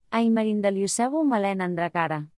Melorin is renowned for its melodic and song-like quality, with a rhythmic cadence that flows effortlessly.
Example sentences